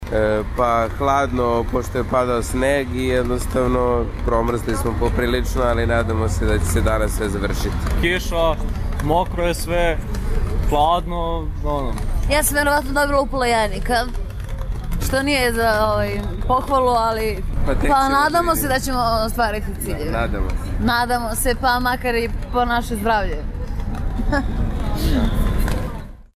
Razgovor sa studentima koji štrajkuju